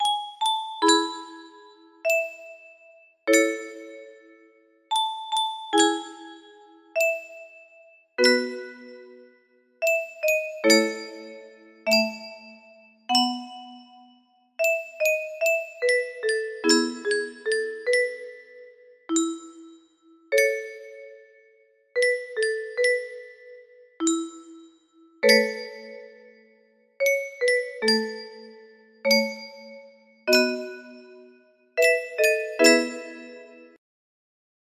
Frost's Song music box melody